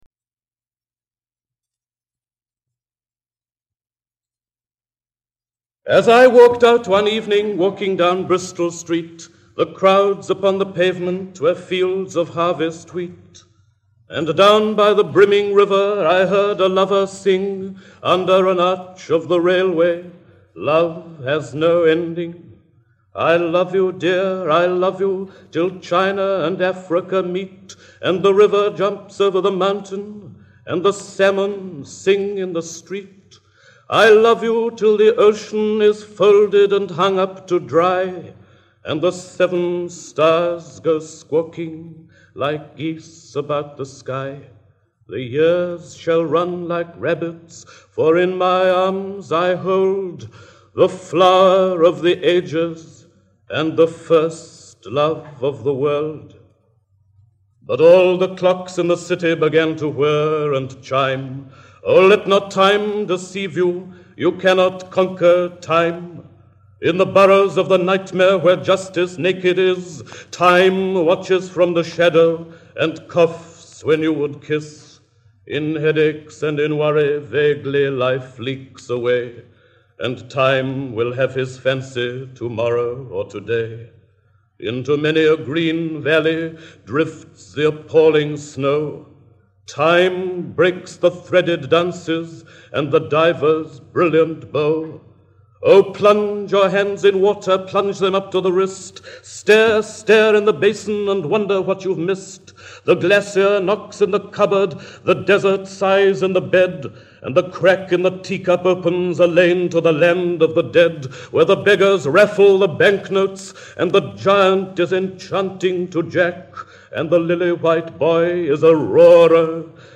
Dylan Thomas lee a Auden: